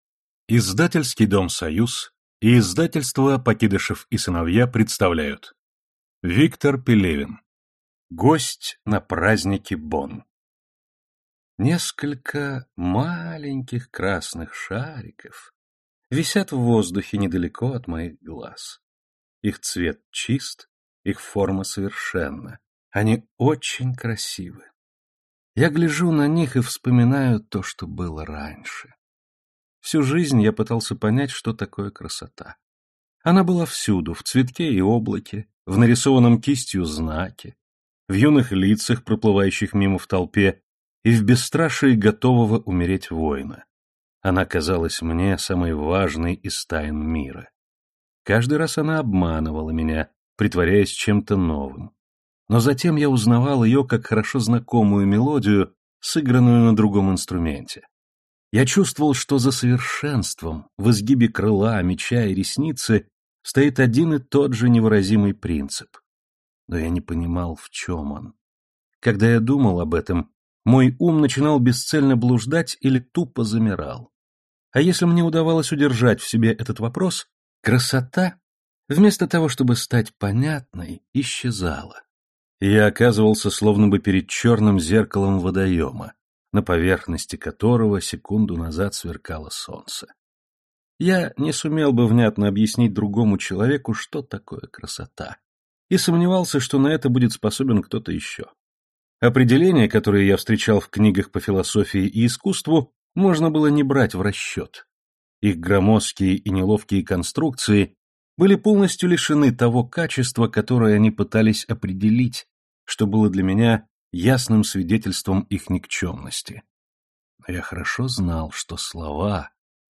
Аудиокнига Гость на празднике Бон | Библиотека аудиокниг